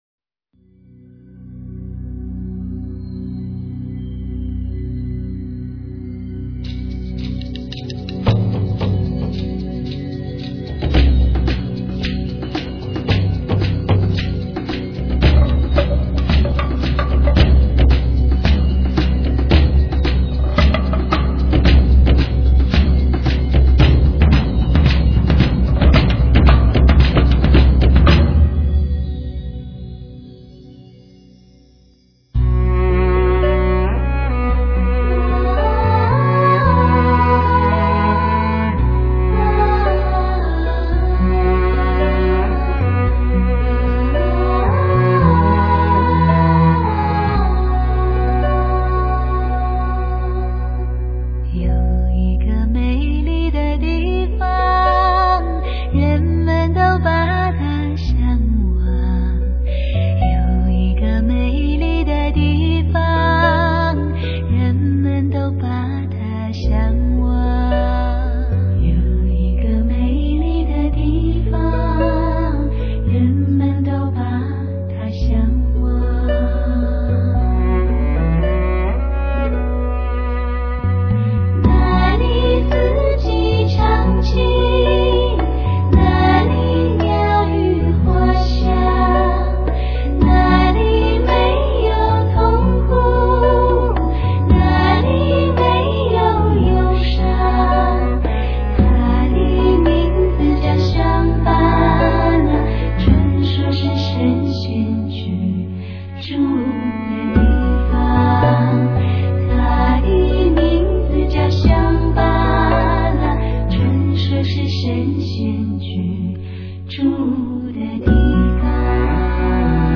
充满感性魅力的女子组，唱功深厚，韵味浓郁，HIFI感兼备，
录音立体，音色甜润且层次分明，每件乐器均拥有无敌的分隔度及结像。